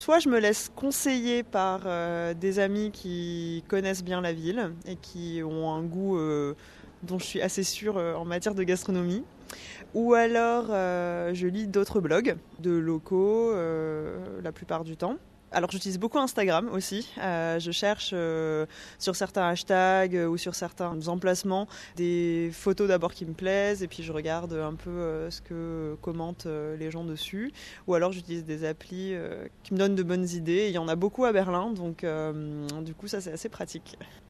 Elle m’a fait parvenir les éléments de l’interview (radio), que j’ai le plaisir de partager avec vous ici.